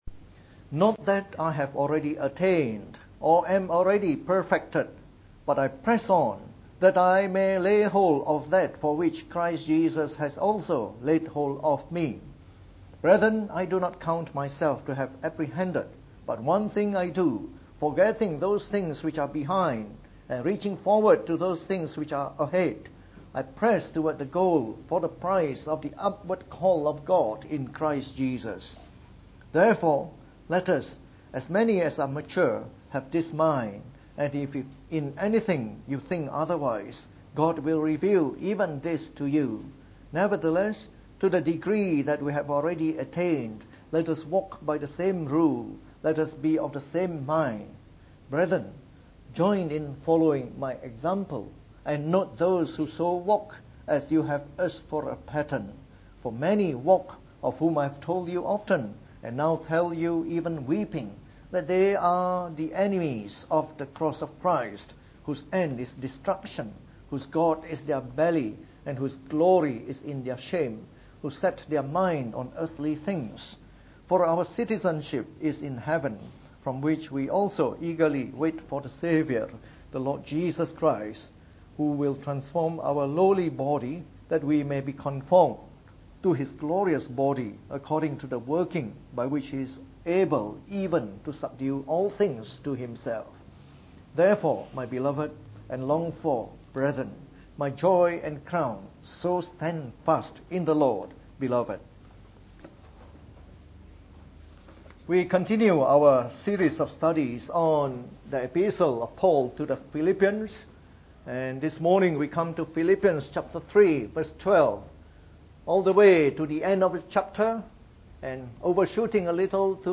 Preached on the 28th of February 2016. From our series on the Epistle to the Philippians delivered in the Morning Service.